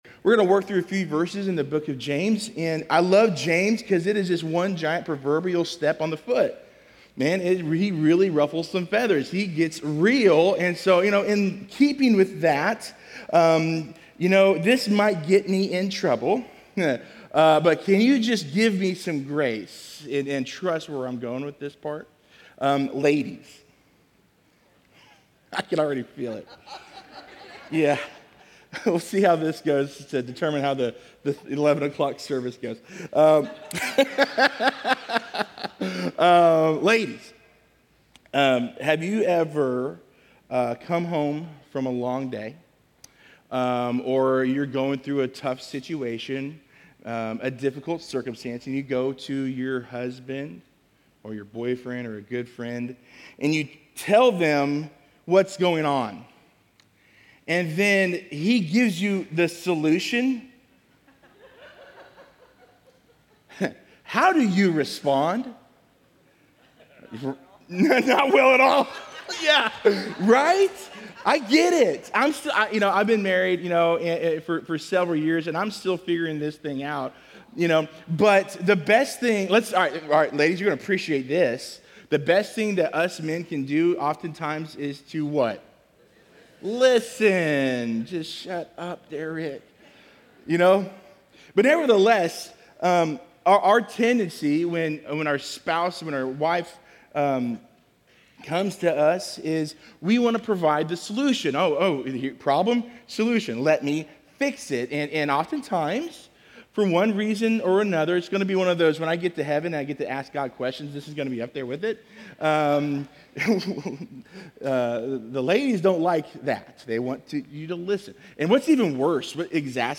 Last sunday's sermon